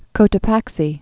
(kōtə-păksē, -tō-päk-)